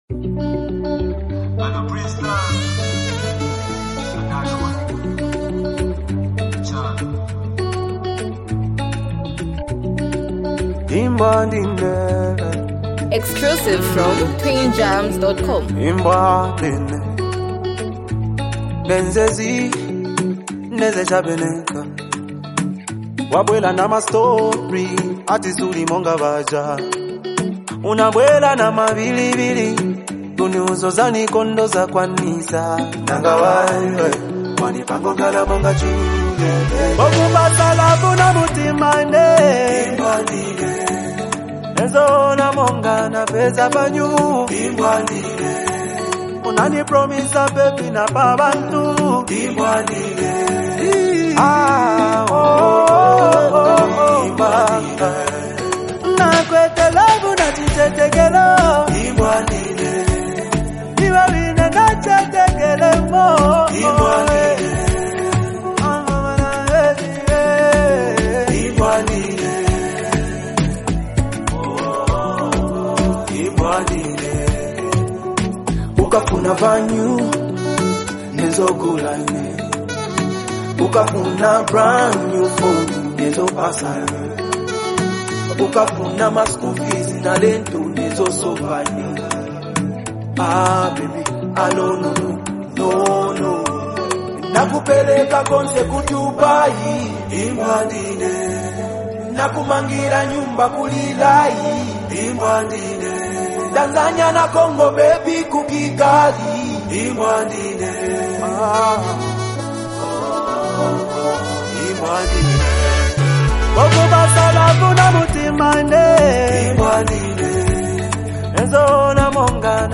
deeply emotional and expressive song